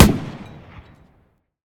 tank-mg-shot-4.ogg